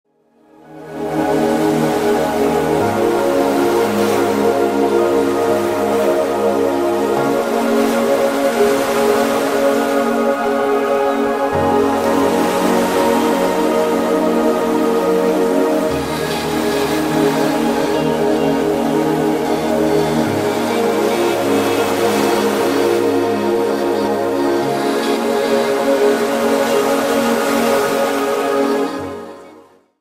RELAXATION MUSIC  (03.42)